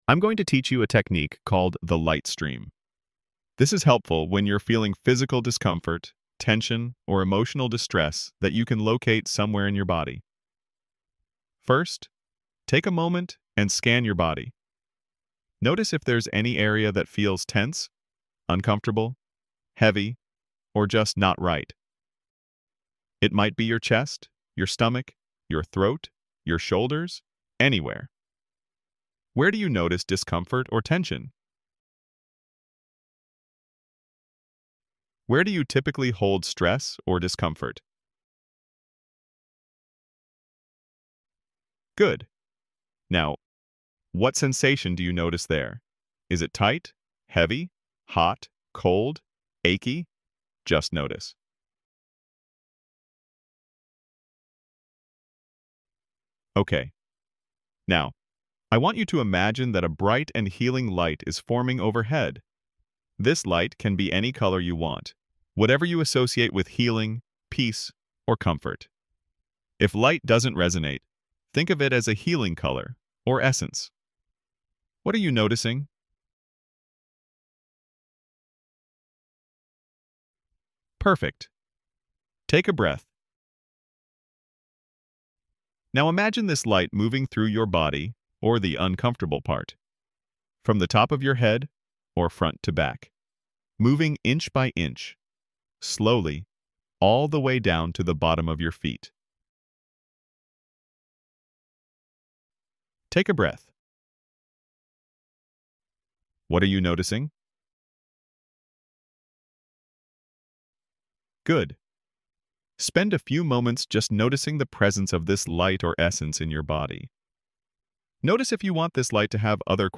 Free audio-guided exercises for EMDR Phase 2 preparation